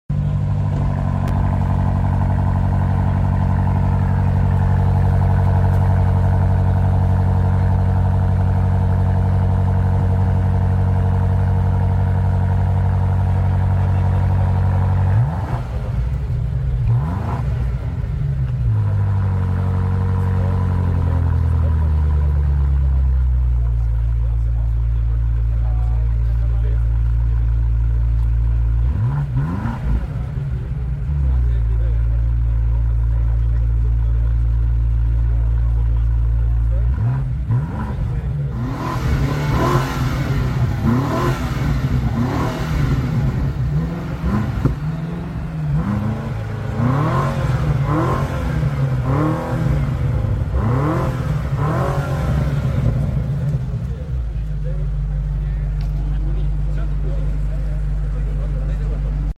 2025 BMW M5 | start up sound & revs